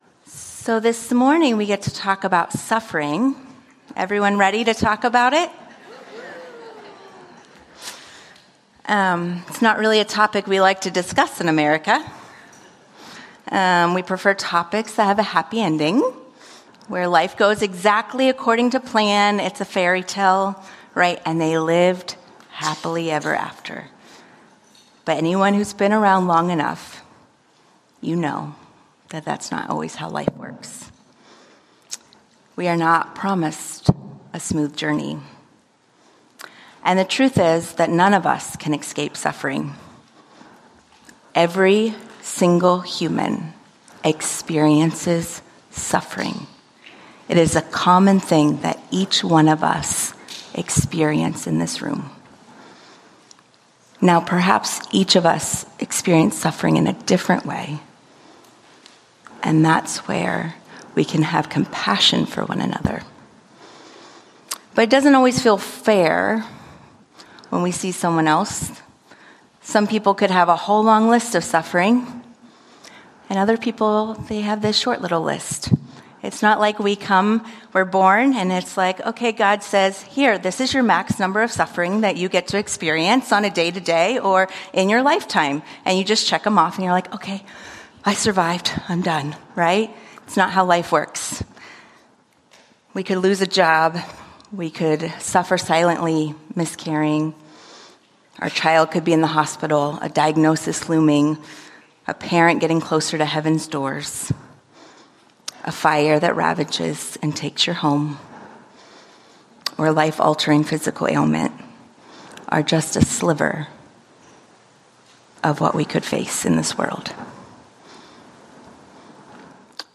Lecture Slides GRATITUDE